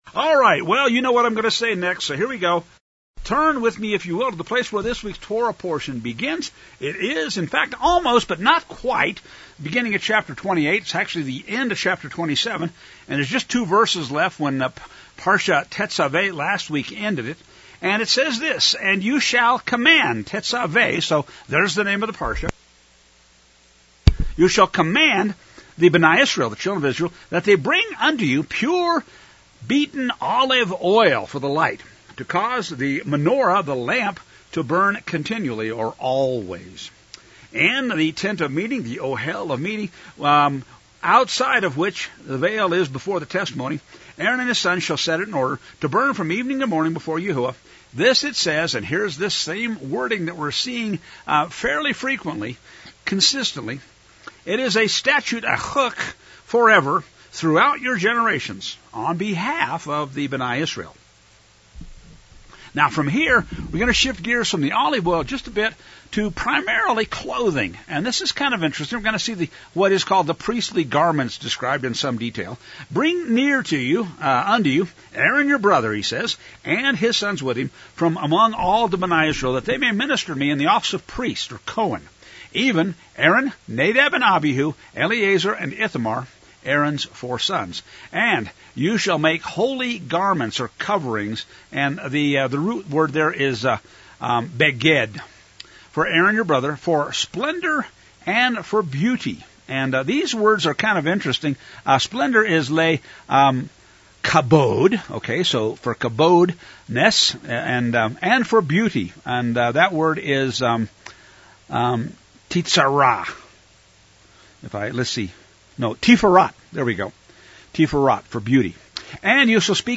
SSM-3-7-25-Tetzaveh-teaching-podcast-x.mp3